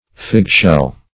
Search Result for " fig-shell" : The Collaborative International Dictionary of English v.0.48: Fig-shell \Fig"-shell`\, n. (Zool.) A marine univalve shell of the genus Pyrula , or Ficula , resembling a fig in form.